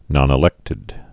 (nŏnĭ-lĕktĭd)